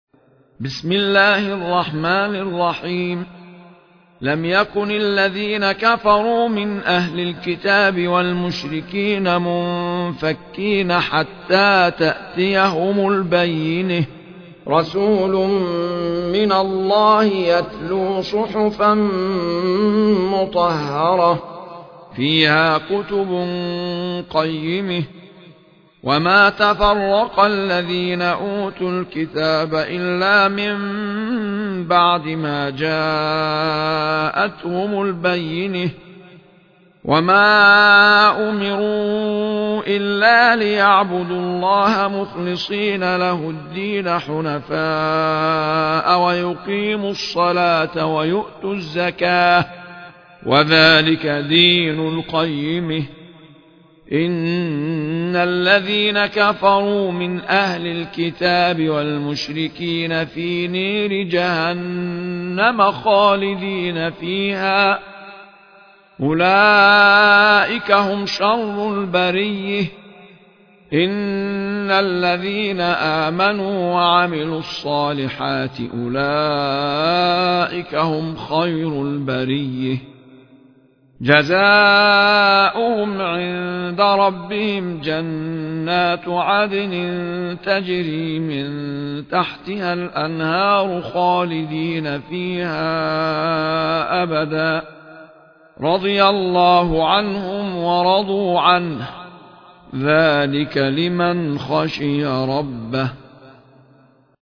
المصاحف - أحمد عيسى المعصراوي
المصحف المرتل - ابن وردان عن أبي جعفر